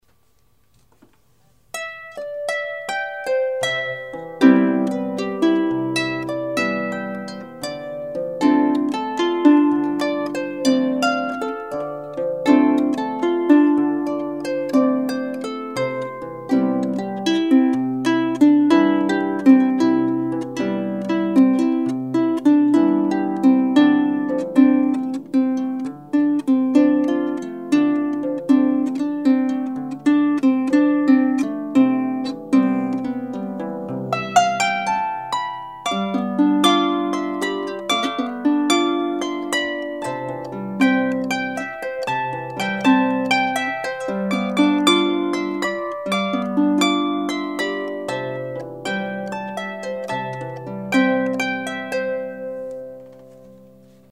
Quello di oggi é un temino di sapore latino, una “palometta” appunto.